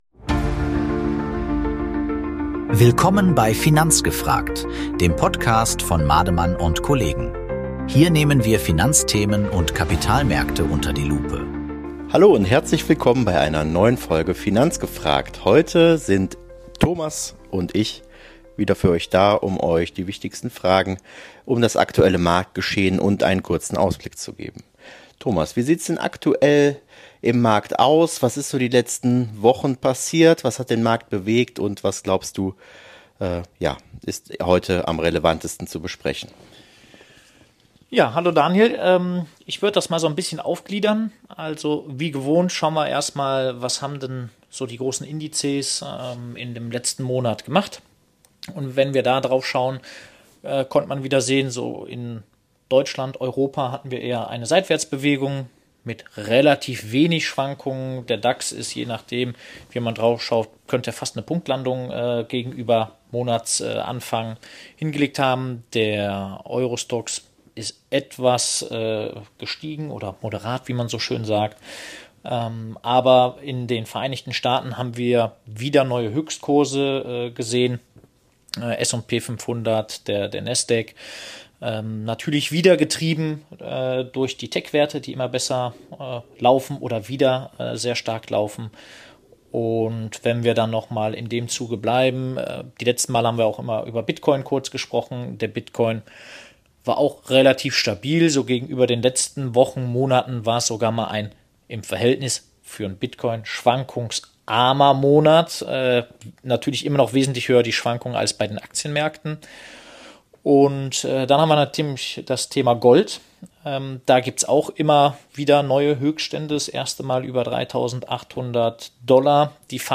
Diesmal kein klassisches Interview, sondern echter Austausch unter Experten.